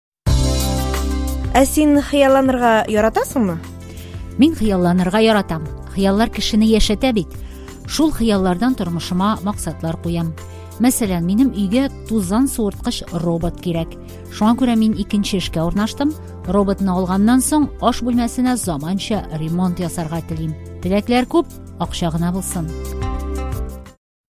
Послушайте три аудио, где журналист спрашивает собеседника о желаниях и способах их достижения.